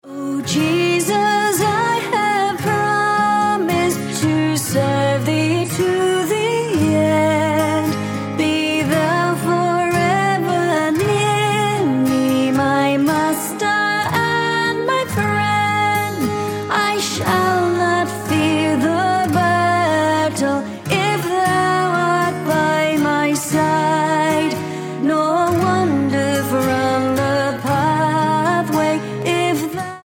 Db